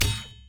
sword2.wav